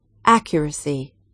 accuracy /1000/ /’æk.jər.əs.i/ /’æk.jər.əs.i/